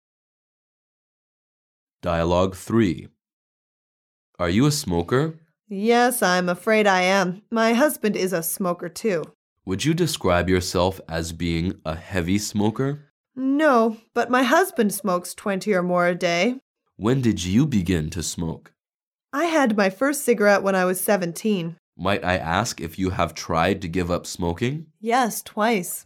Dialouge 3